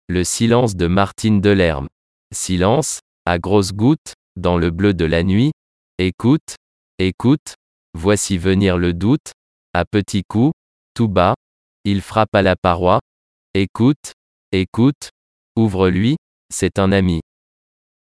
SILENCE, VOIX DE SYNTHÈSE (RNS11)
L1_34_P_poeme_RNS11_silence_synthese.mp3